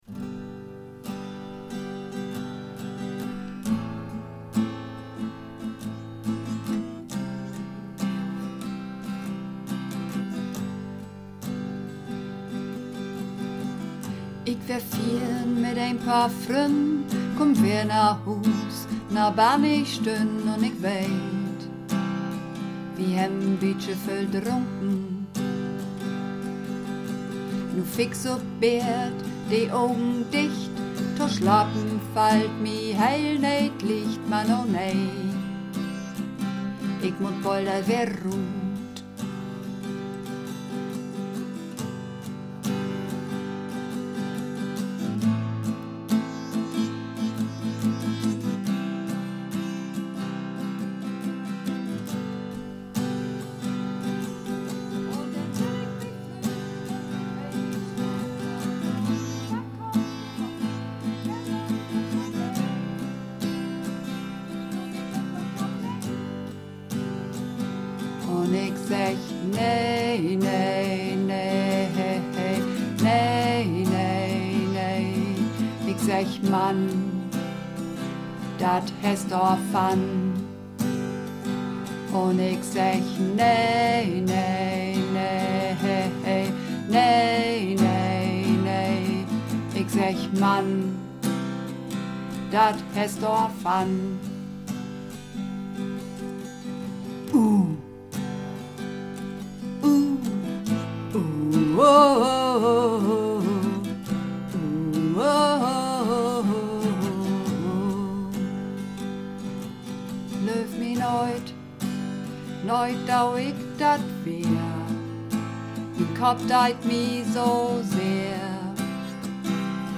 Übungsaufnahmen - Dat hest daarvan
Runterladen (Mit rechter Maustaste anklicken, Menübefehl auswählen)   Dat hest daarvan (Bass)